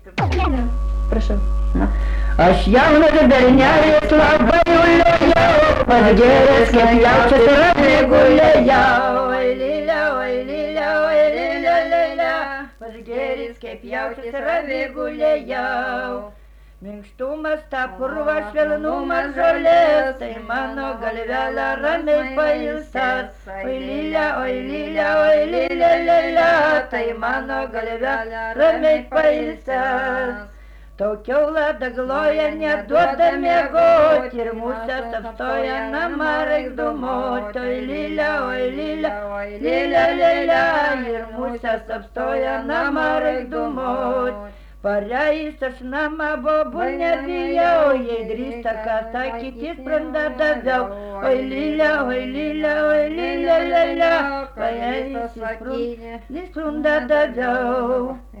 smulkieji žanrai
Erdvinė aprėptis Trumponys
Atlikimo pubūdis vokalinis